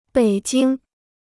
北京 (běi jīng): Beijing, capital of the People's Republic of China.